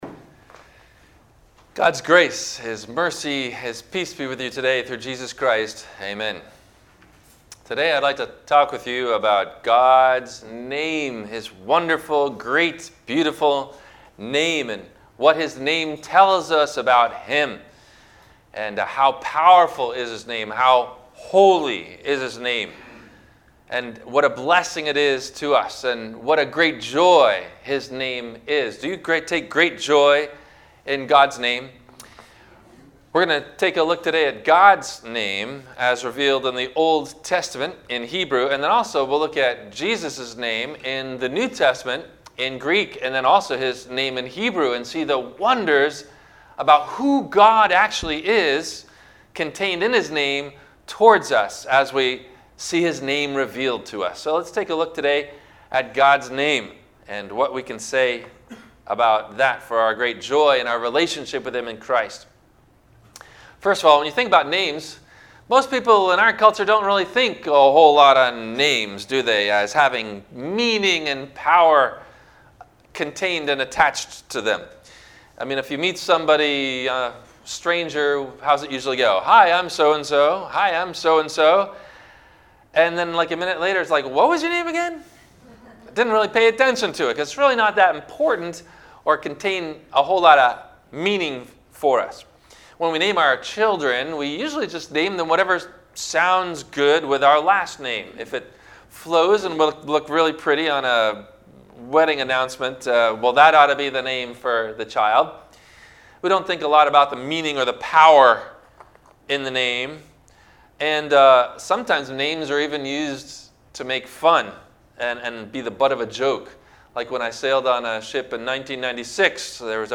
- Sermon - February 07 2021 - Christ Lutheran Cape Canaveral